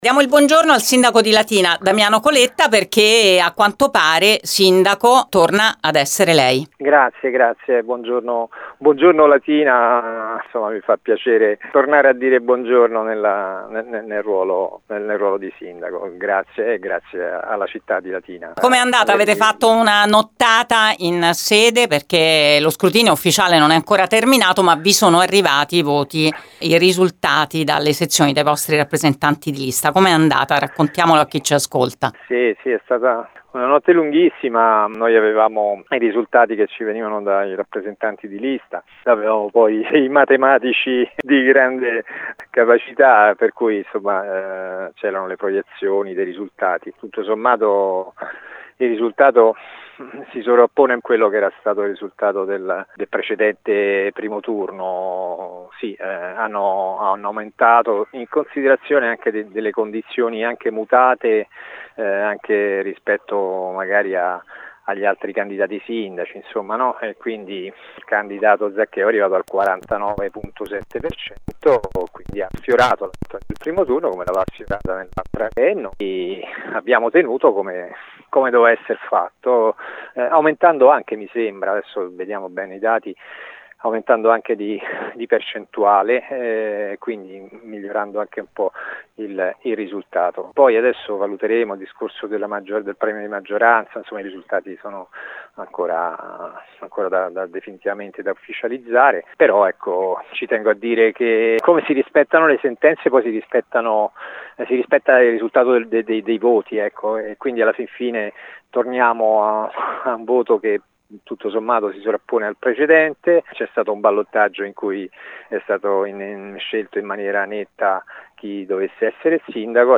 Le prime parole di Damiano Coletta questa mattina su Radio Immagine